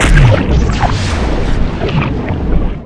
SOUND / WEAPON / GRENADE